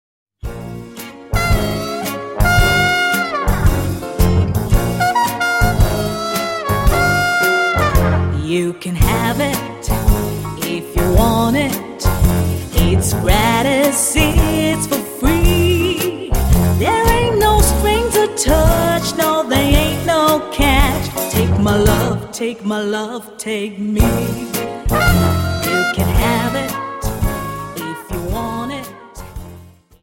Dance: Slowfox 28s